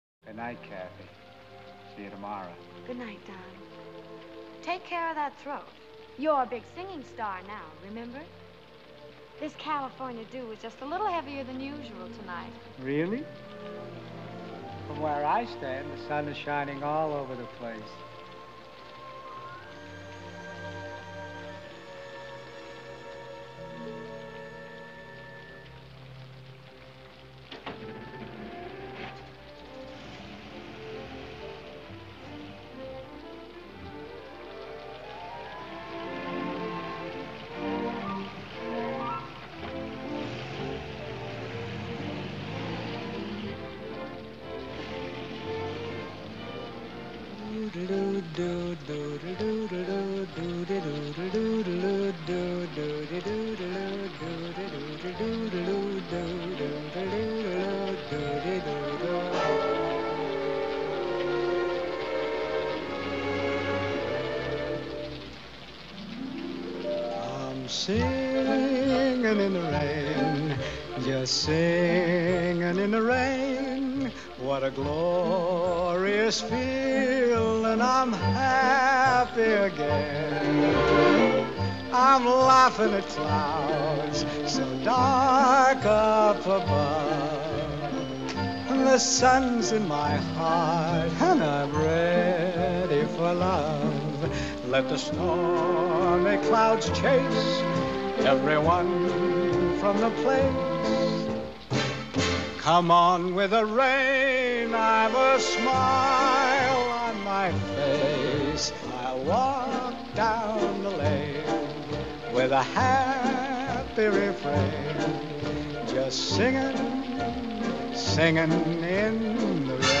ordinary raspy voice
1929   Genre: Soundtrack   Artist